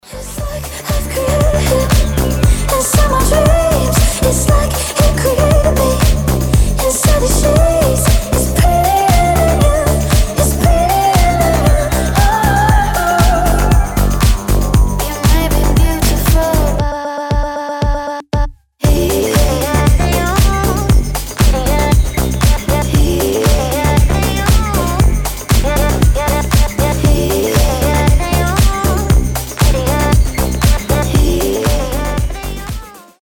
• Качество: 320, Stereo
indie pop